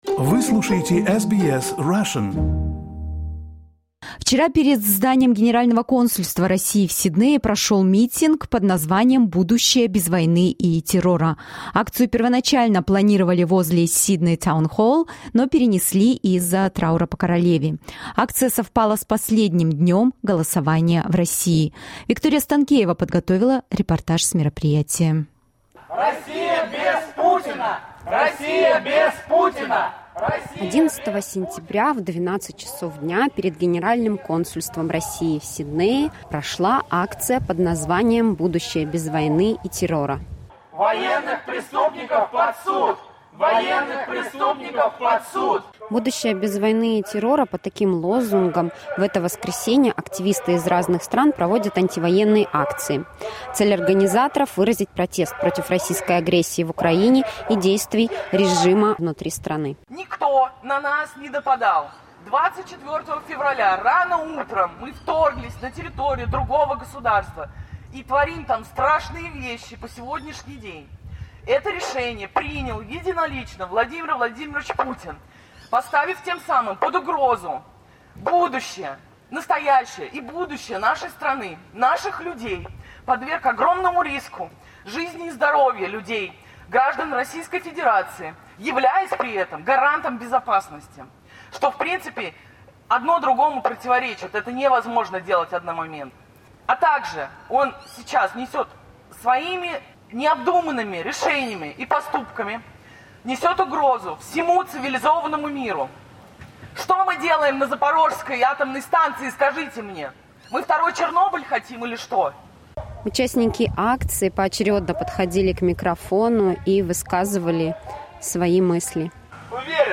Репортаж с акции возле Генерального Консульства РФ в Сиднее